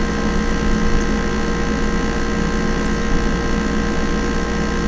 vendmachine.wav